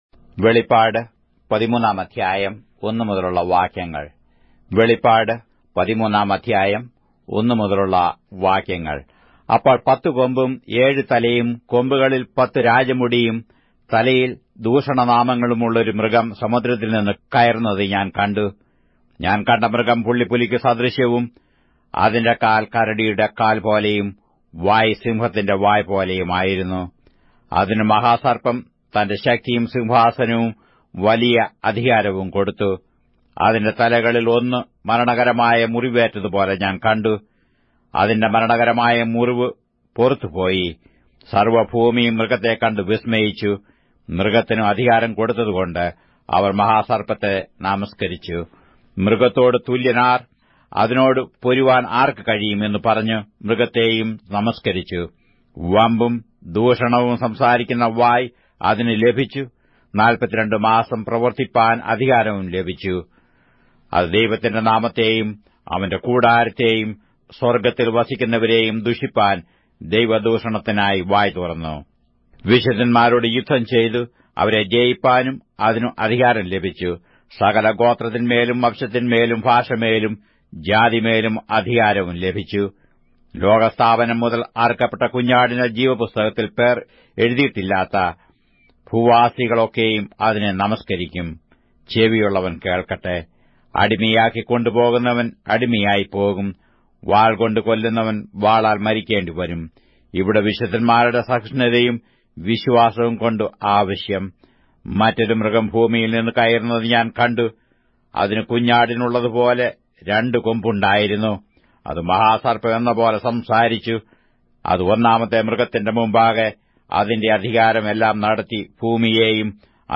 Malayalam Audio Bible - Revelation 12 in Mov bible version